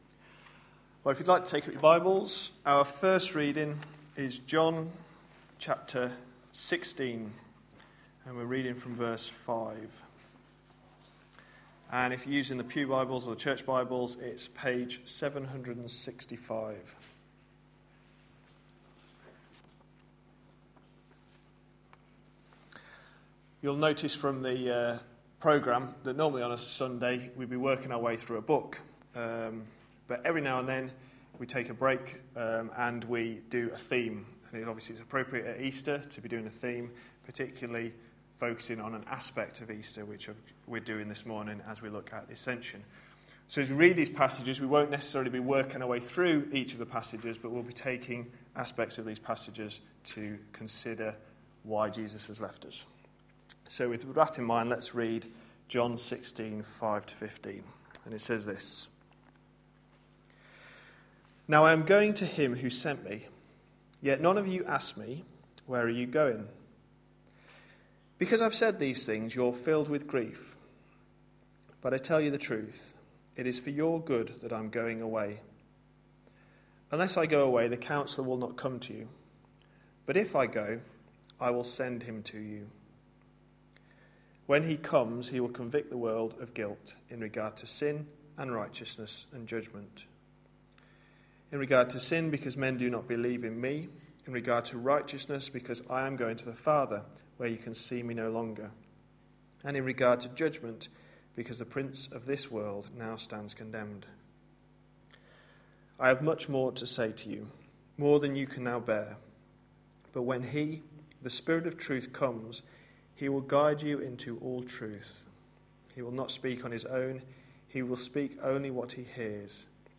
A sermon preached on 31st March, 2013.